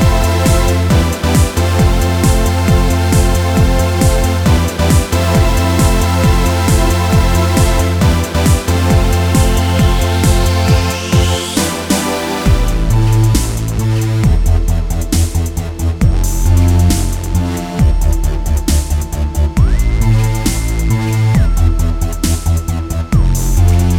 no Backing Vocals Dance 3:23 Buy £1.50